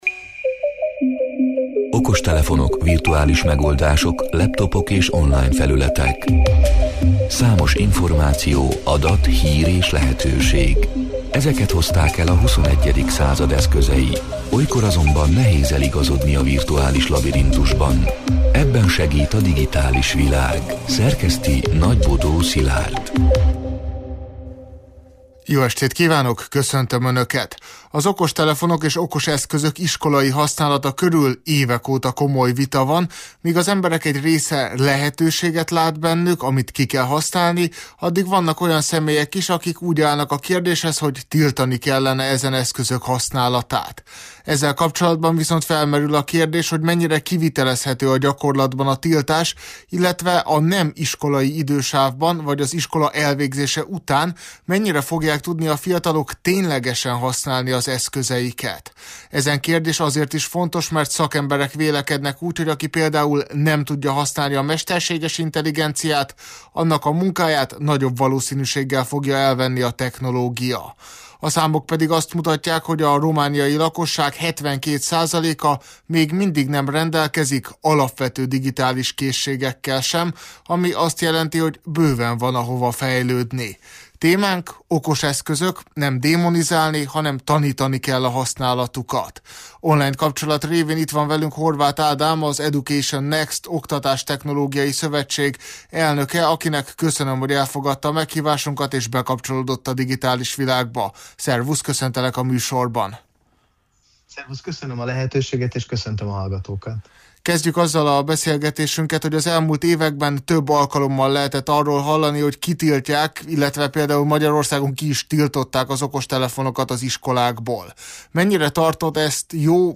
A Marosvásárhelyi Rádió Digitális Világ (elhangzott: 2026. március 3-án, kedden este órától élőben) c. műsorának hanganyaga: